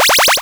levelup3.wav